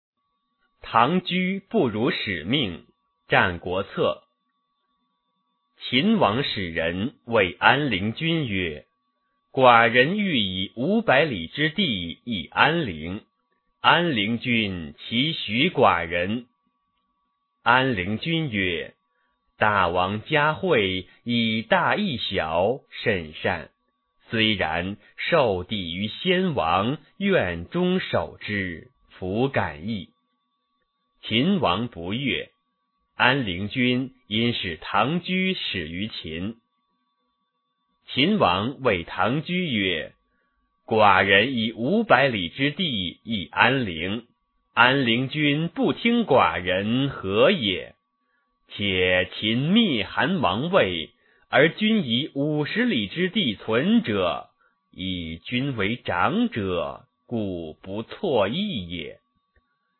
九年级语文下册10唐雎不如使命》男声高清朗读（音频素材）